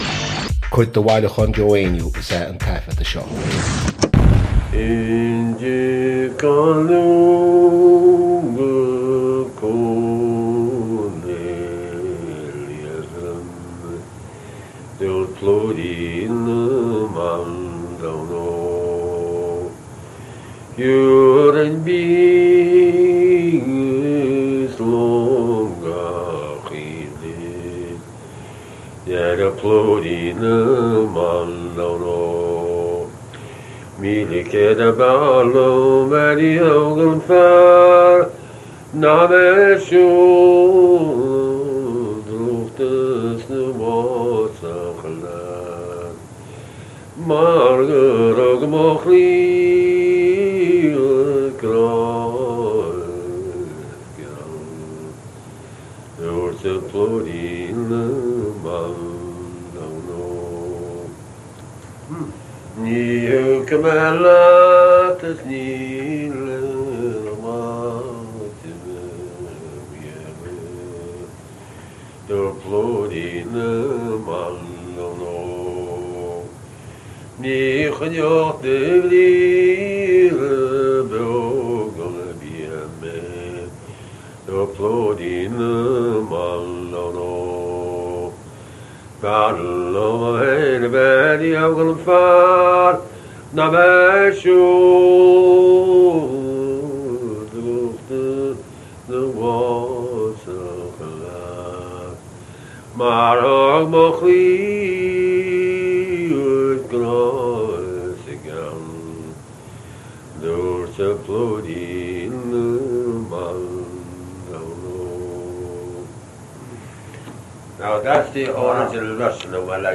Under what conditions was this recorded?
• Suíomh an taifeadta (Recording Location): Wesleyan University, Middletown, Connecticut, United States of America.